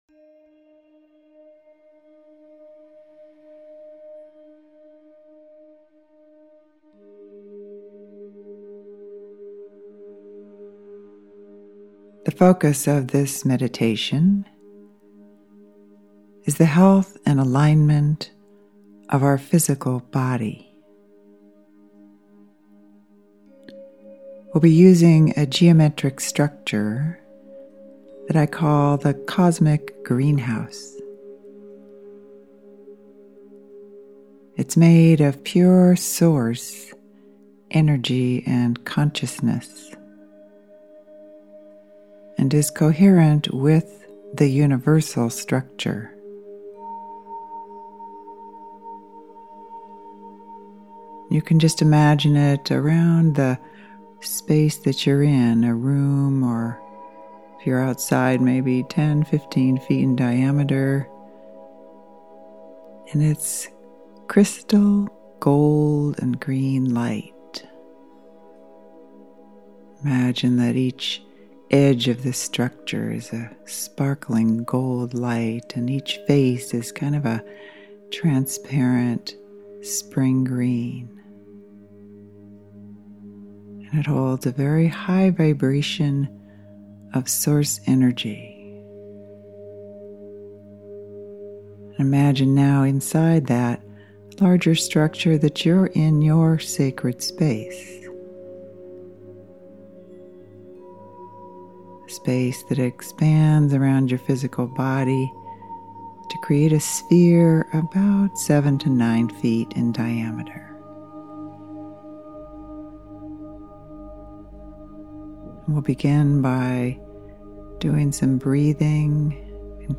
Step into the Cosmic Greenhouse to align and heal your physical body. Dissolve any fear of pain, discomfort and aging with these healing words and music.